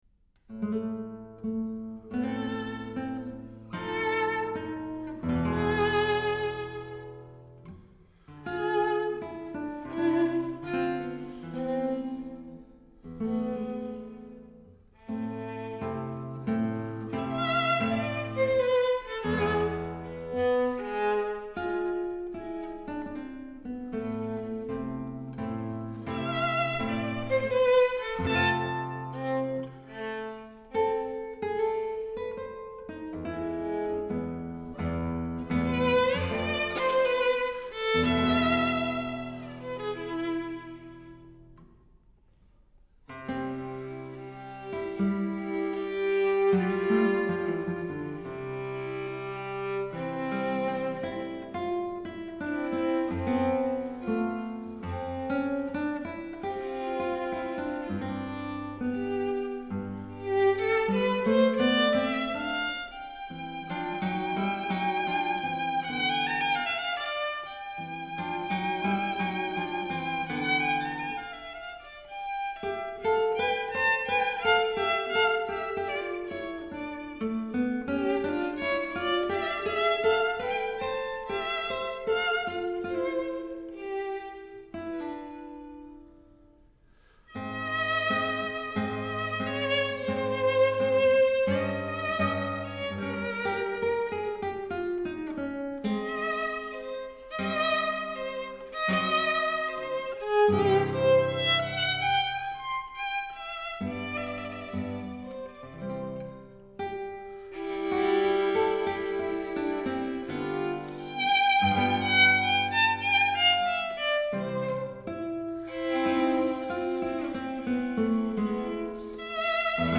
室内乐